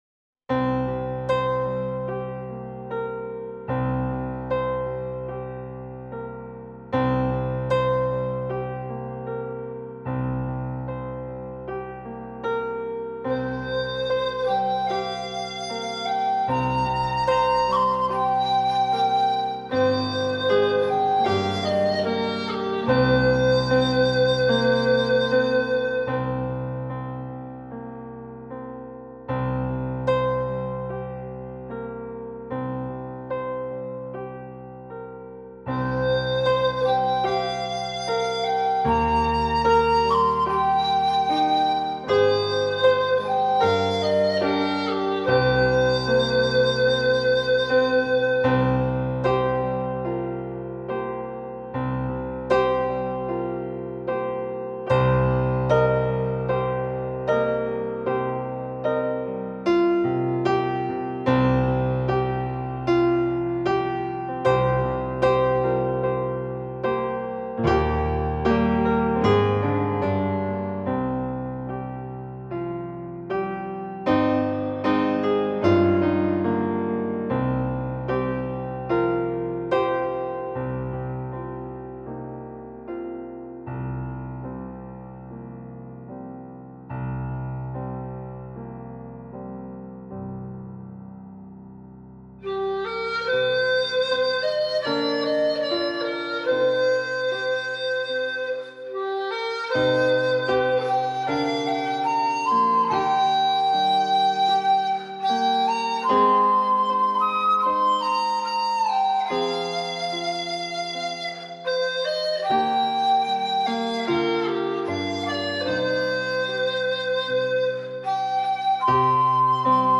Chinesische Bambusflöte